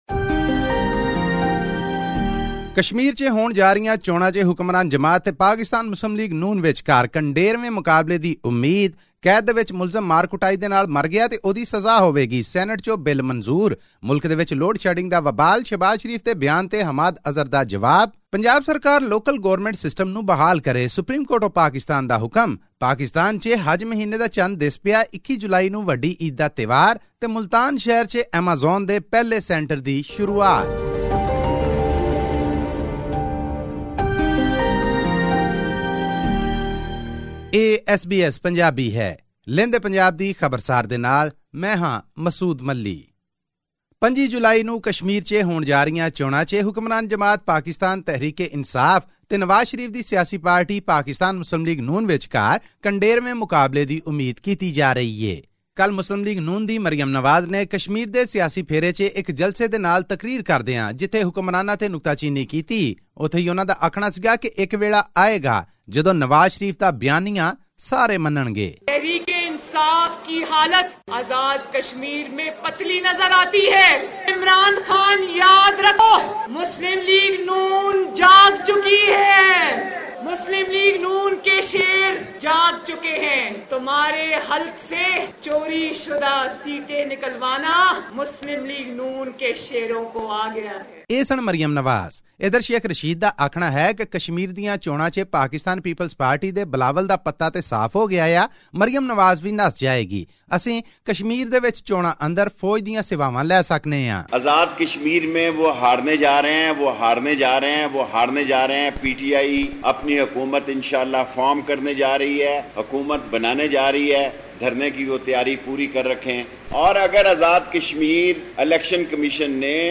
The Senate on 12 July passed a bill criminalising torture and preventing custodial killings by police or other government officials. This and more in our weekly news bulletin from Pakistan.